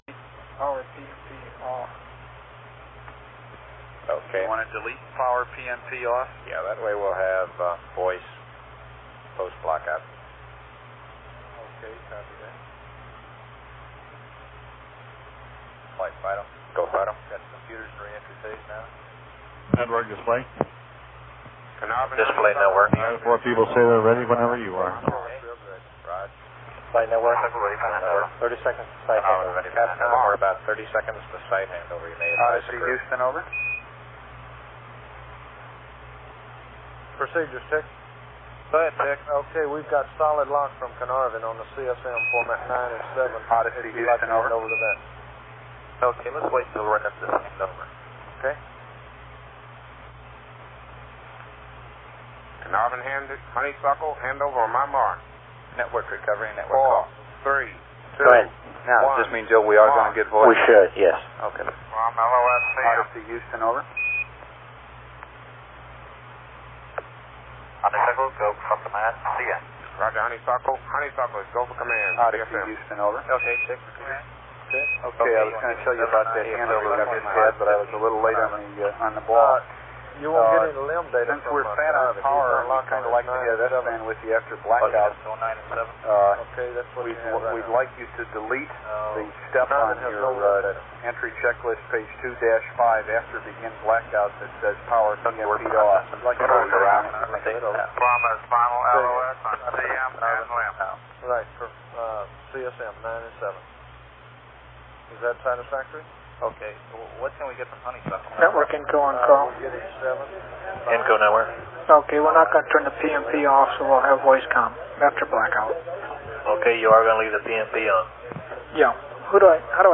Audio is from the Network Controller’s loop.